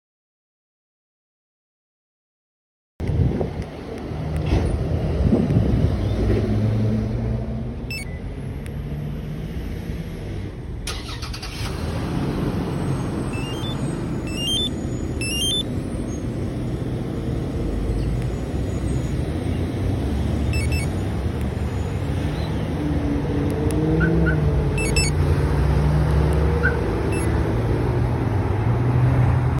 Compustar t13 alarm and remote sound effects free download